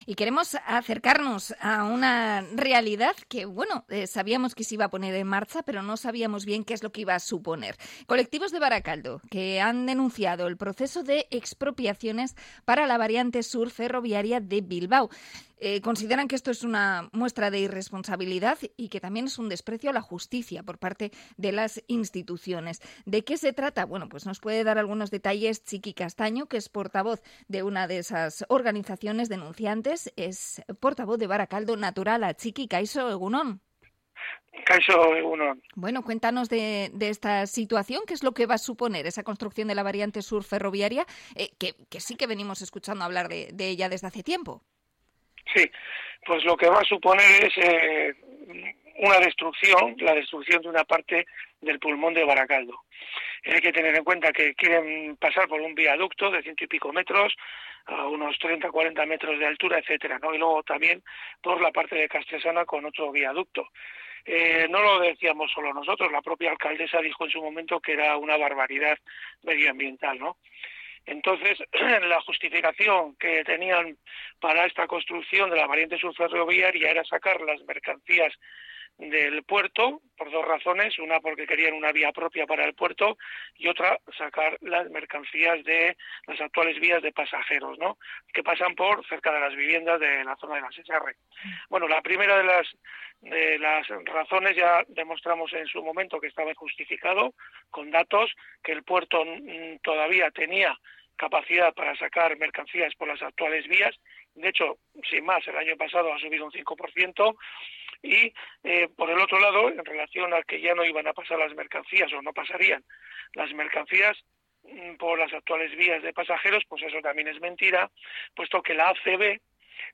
Entrevista a Barakaldo Naturala por la Variante Sur Ferroviaria